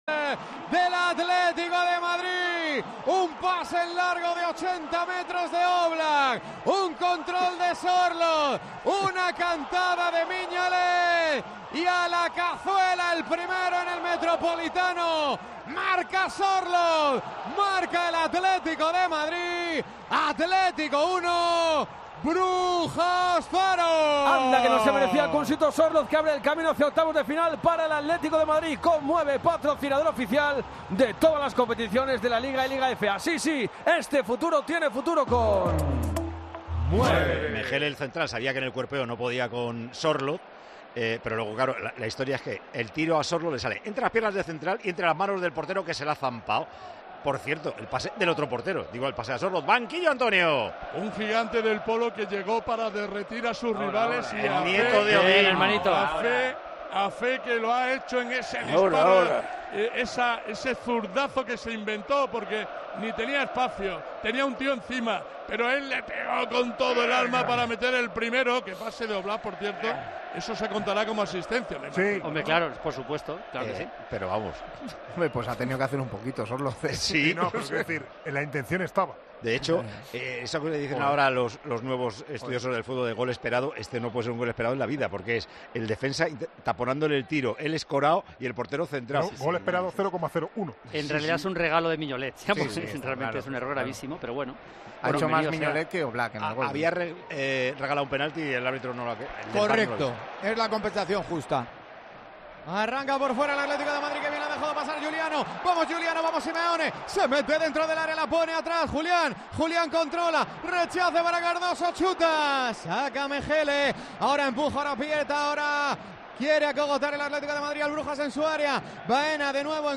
Paco González y el equipo de 'Tiempo de Juego' analizan el increíble tanto del noruego que encarrila el pase del Atlético a octavos de final de la Champions